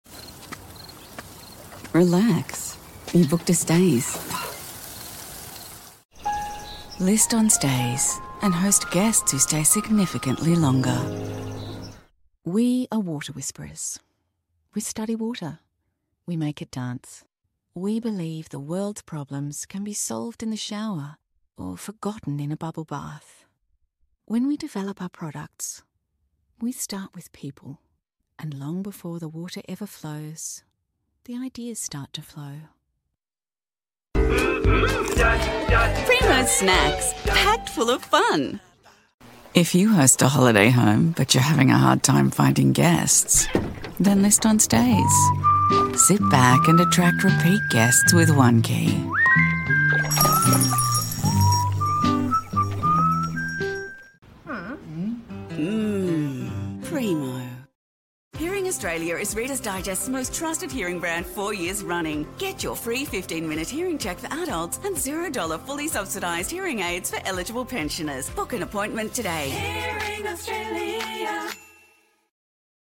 Australian Female Voice Over Artists, Talent & Actors
Adult (30-50) | Older Sound (50+)